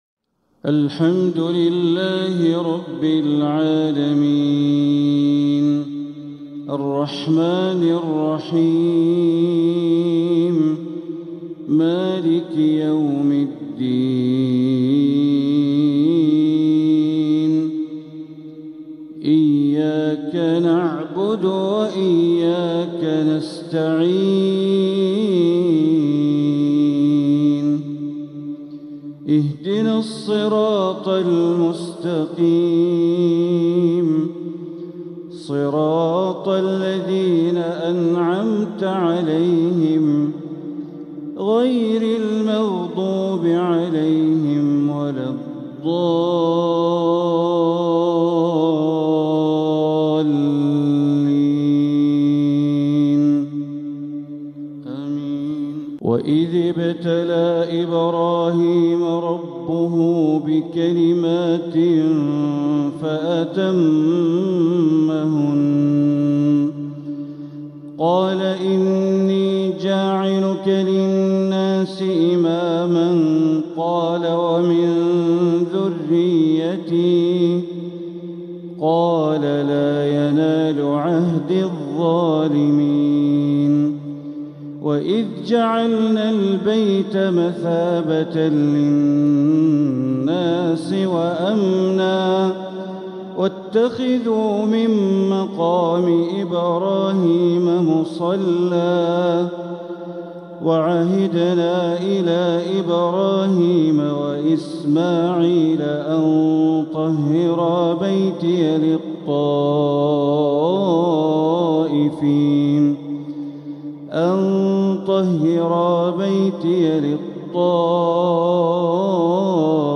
سلسلة اللآلئ الأصيلة لتلاوات الشيخ بندر بليلة لتلاوات شهر ذو الحجة 1446 الحلقة السابعة والسبعون > سلسلة اللآلئ الأصيلة لتلاوات الشيخ بندر بليلة > المزيد - تلاوات بندر بليلة